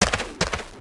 Heroes3_-_Pit_Fiend_-_MoveSound.ogg